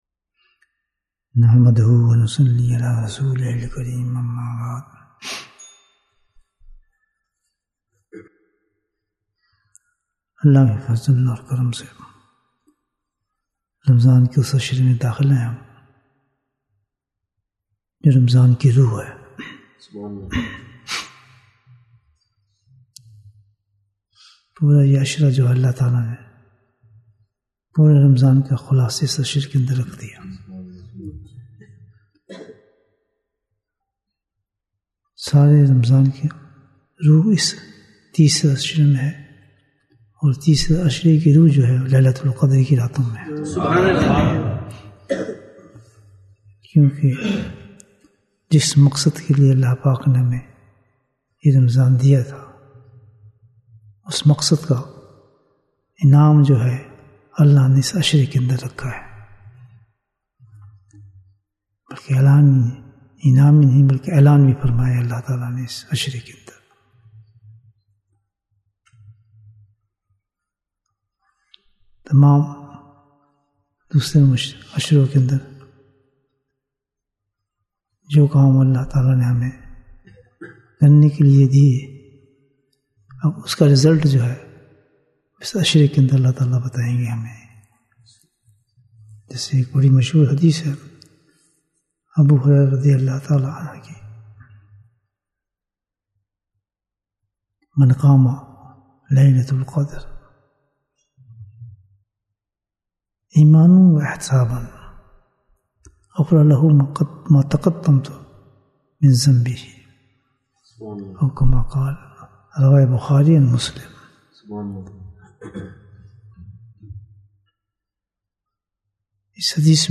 Bayan, 40 minutes12th April, 2023